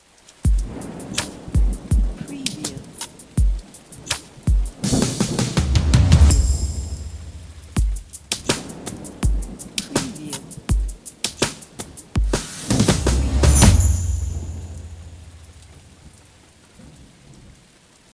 لوپ مارکتی ریتم و پاساژ
معرفی پکیج لوپ ۲/۴ مارکتی به همراه پاساژ
• کیفیت بالا: تمامی لوپ ها با کیفیت بسیار بالا تولید شده اند و از مدرن ترین سمپل های وان شات استفاده شده است.
• میکس شده و آماده استفاده: تمامی ریتم ها و پاساژها میکس شده و آماده استفاده هستند، بنابراین نیازی به تنظیمات اضافی ندارید.